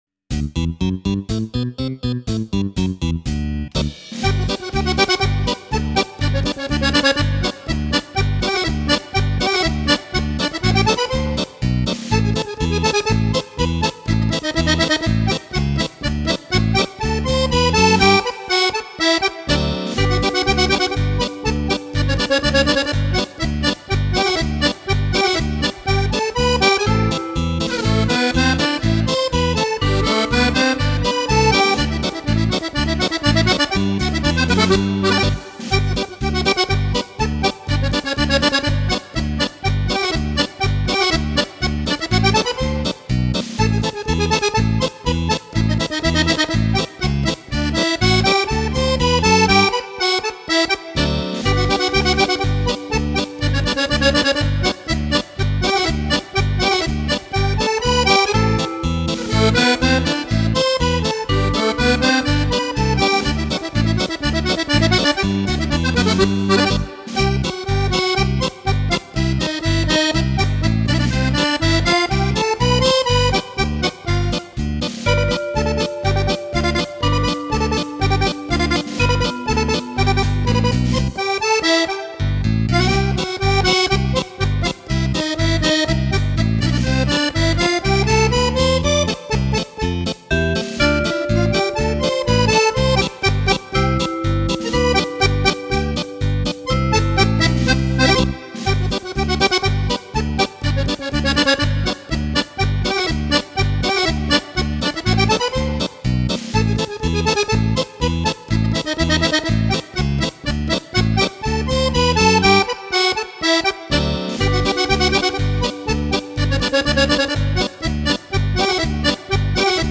Polka
14 ballabili per Fisarmonica  di facile esecuzione.